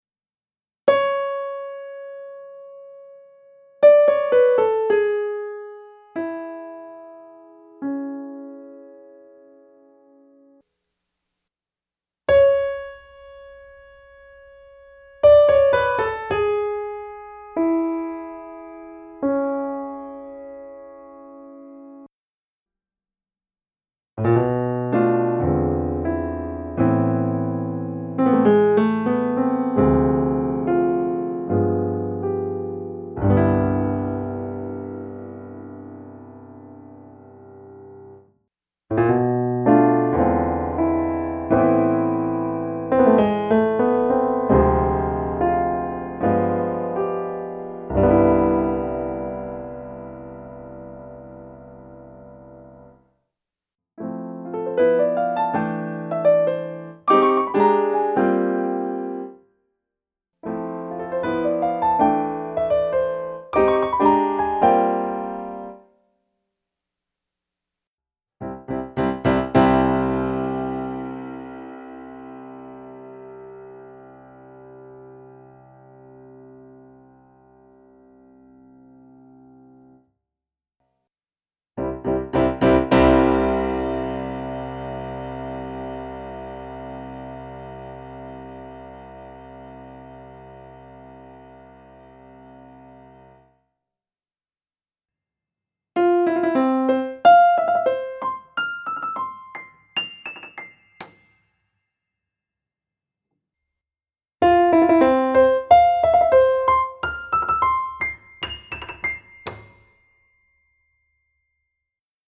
In the example below, you’ll hear five phrases with the first being the Kronos German Grand followed by the Roland Superior Grand. Both were fed an identical MIDI file data.
• The Kronos sounds like a high-quality recording/sampling of a piano; the Roland sounds like a digital piano/ROMpler—it sounds artificial.
• The Kronos properly interrupts the phrase dynamics; the Roland does not and comes across as having a compressed dynamic range (the first and last phrases are best for this observation).
• In the first and especially the fourth example, you can clearly hear the artificial envelopes and sample loops on the Roland (extremely bad in the fourth example).
Kronos German Grand vs. Roland Superior Grand